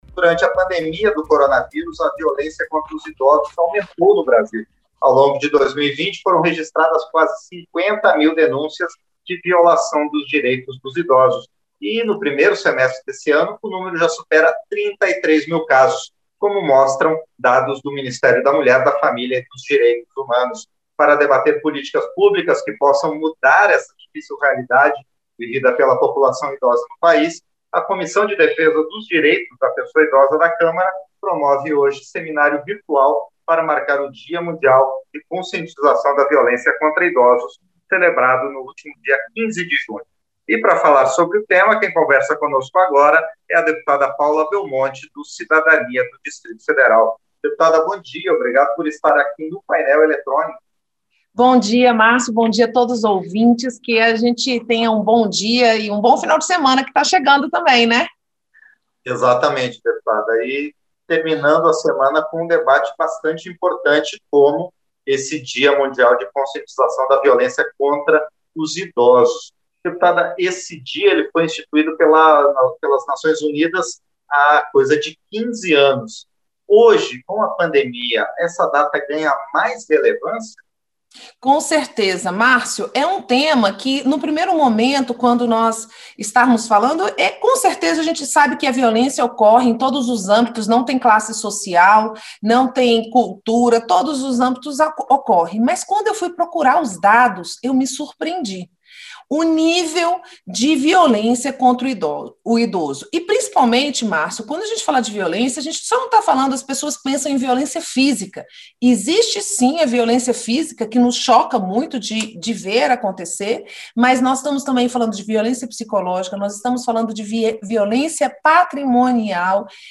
Entrevista - Dep. Paula Belmonte (Cidadania-DF)